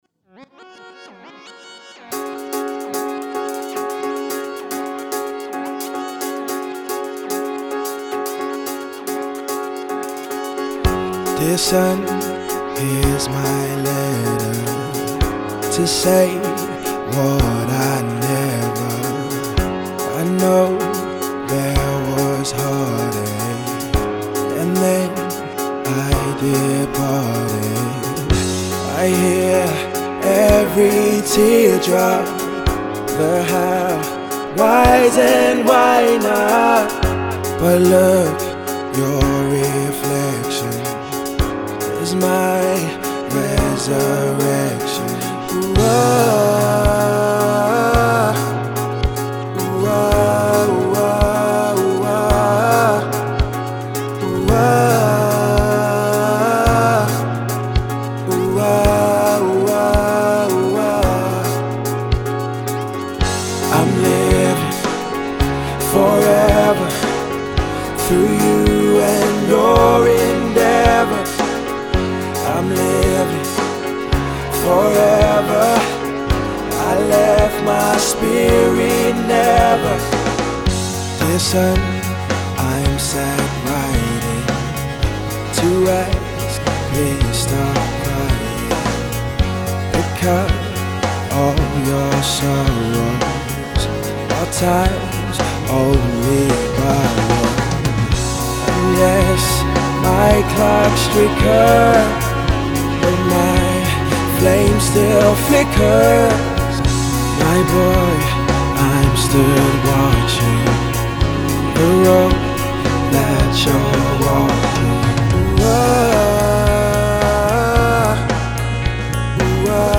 voice has very strong emotive abilities